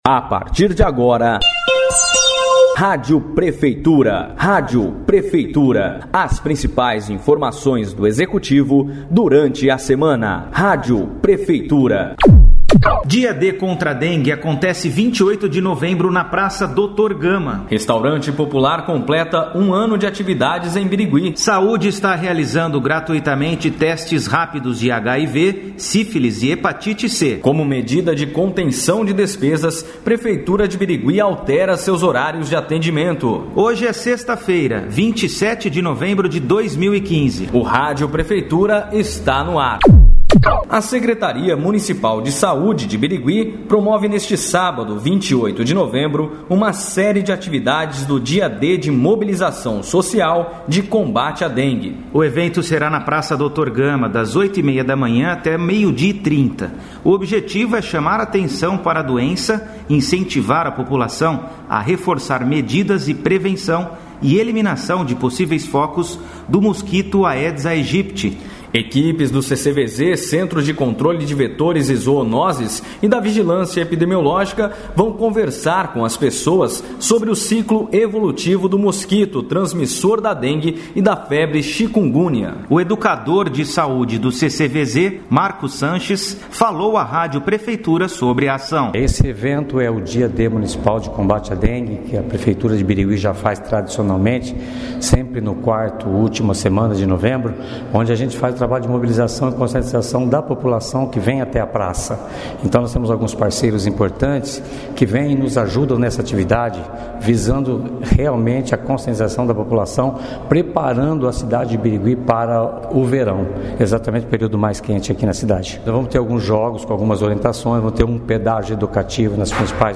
A Prefeitura de Birigui traz um dinâmico programa de rádio com as principais informações da semana do Executivo.
O programa contará, também, com entrevistas abordando temas de interesse coletivo.